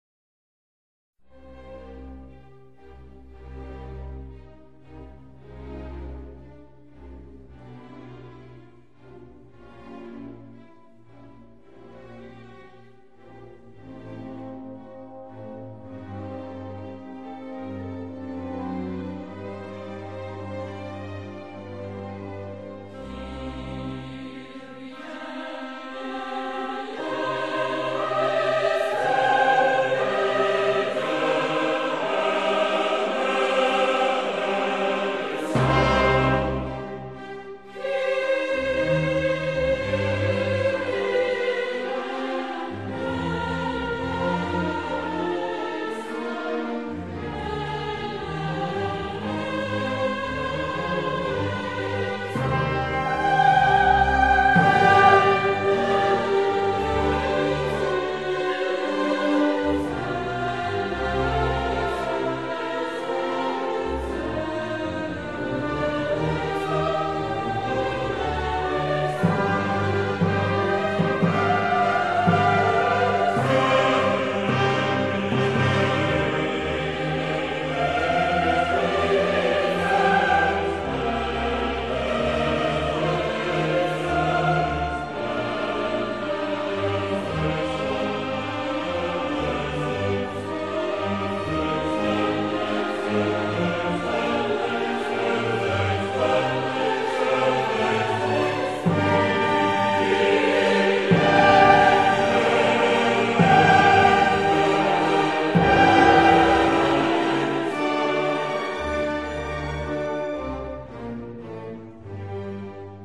great-mass-in-c-minor-kyrie-k.-427-w.a.mozart-audiotrimmer.com_.mp3